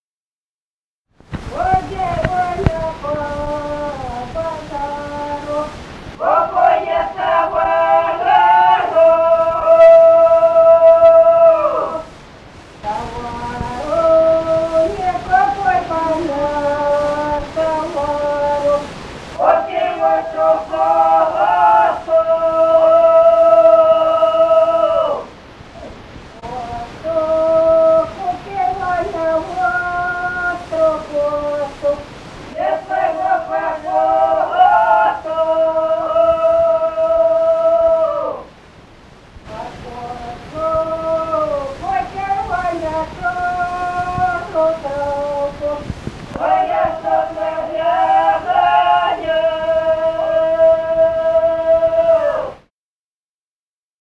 Музыкальный фольклор Климовского района 006. «Ходит Ваня по базару» (масленая).
Записали участники экспедиции